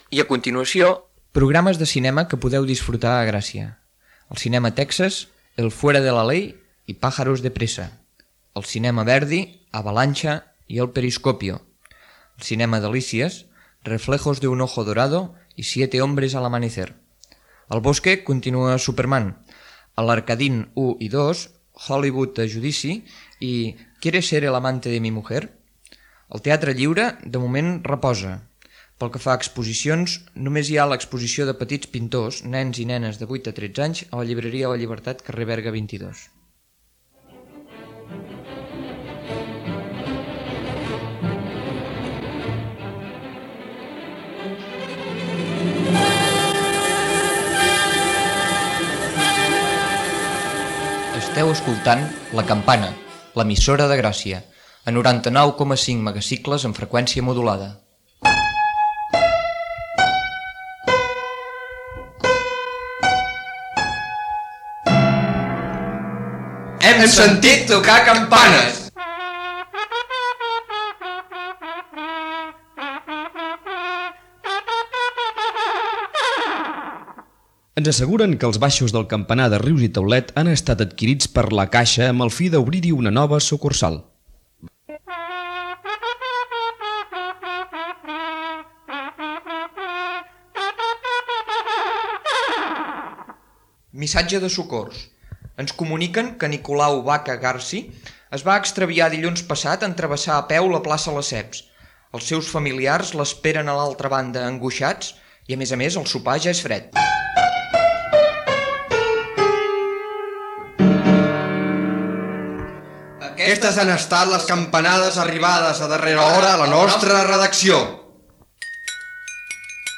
Final del primer programa: cartellera cinematogràfica, indicatiu, missatge de socors, indicatiu, adreces de contacte i dies de les properes emissions, comiat.